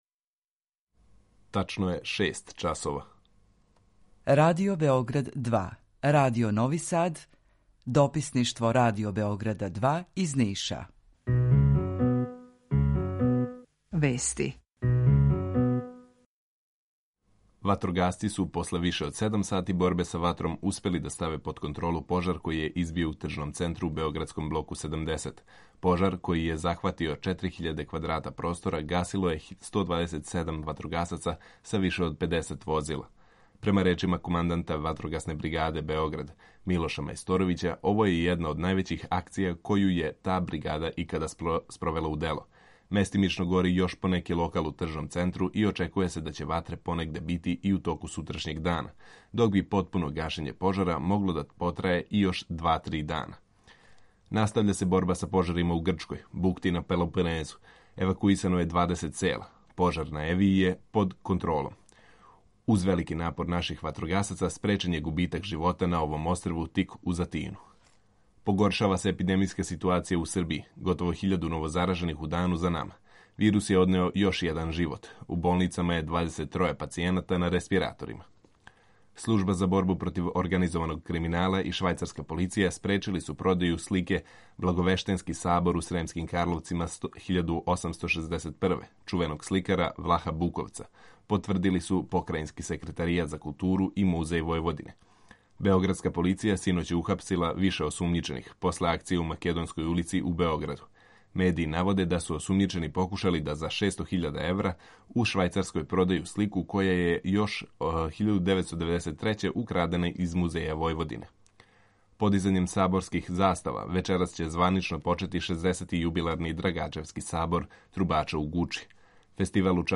Укључење Радија Републике Српске
Јутарњи програм из три студија
У два сата, ту је и добра музика, другачија у односу на остале радио-станице.